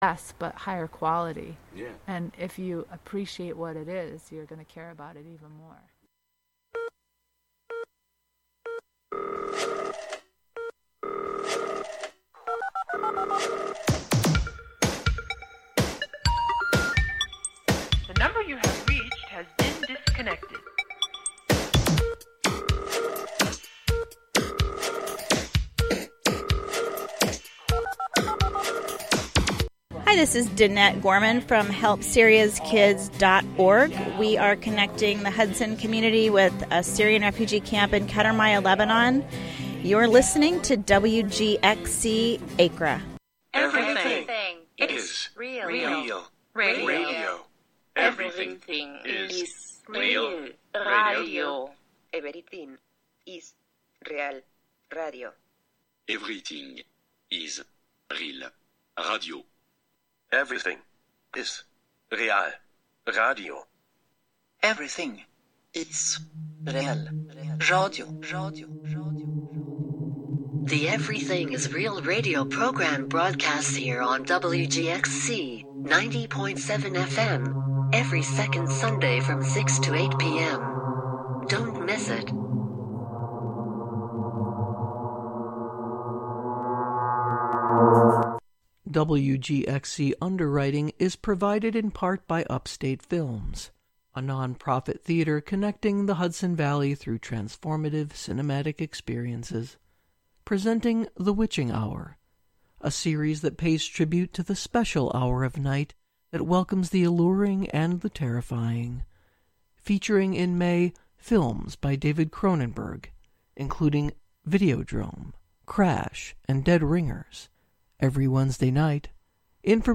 On this monthly program, “La Ville Inhumaine” (The Inhuman City), you will hear music, found sounds, words, intentional noise, and field recordings all together, all at once.